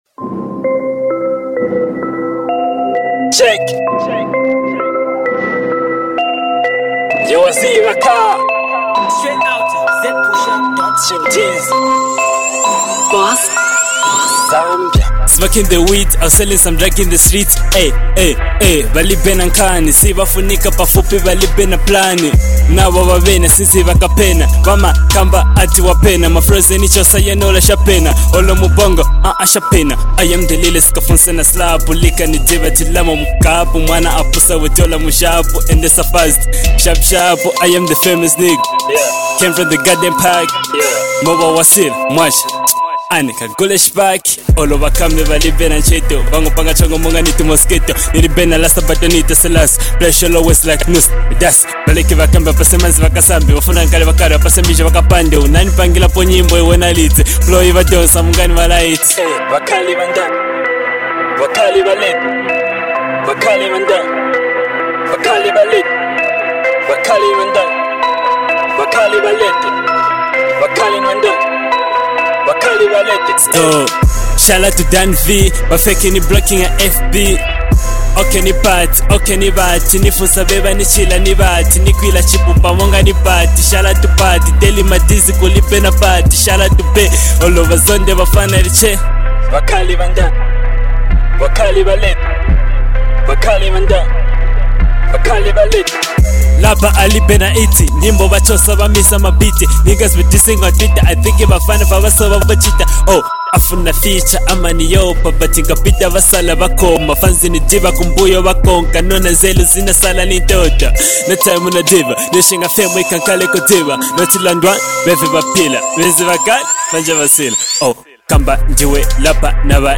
Hip Hop Music Jam